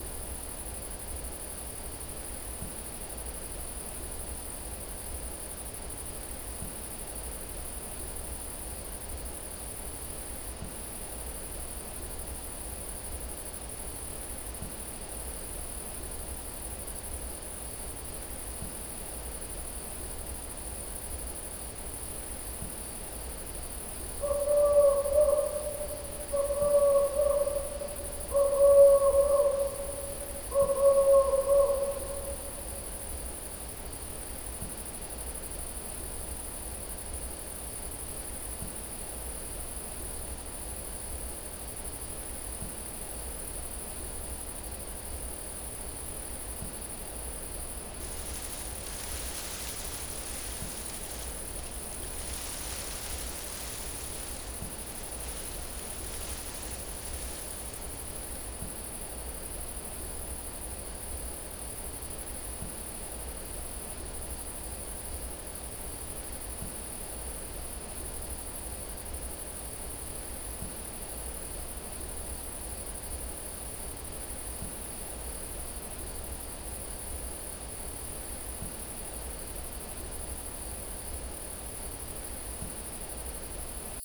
FarmAtmos_Night_01.wav